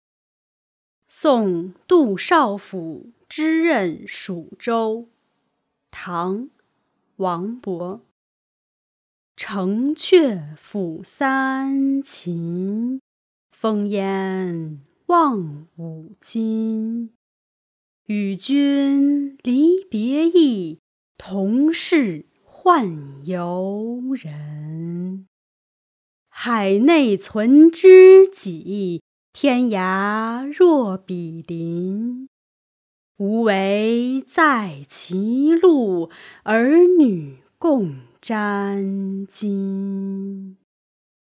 ［唐］王勃 送杜少府之任蜀州（读诵）